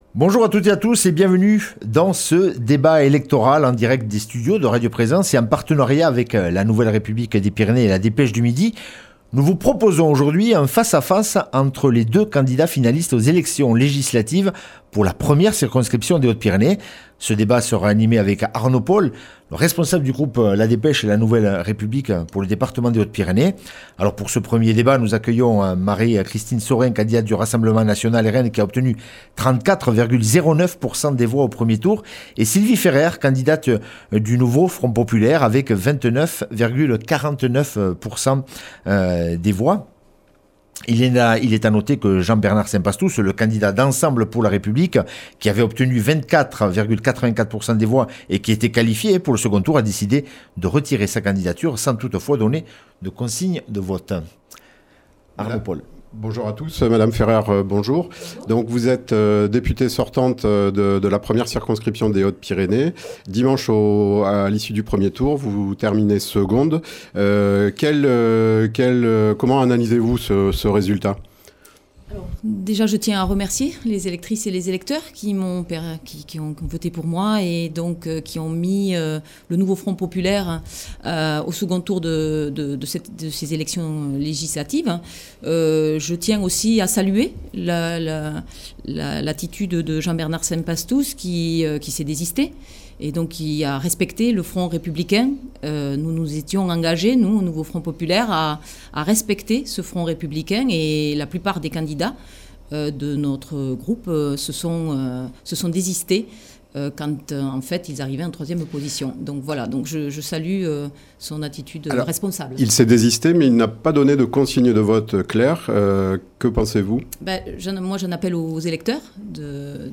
Législatives Hautes-Pyrénées 1re circonscription pour le second tour : le débat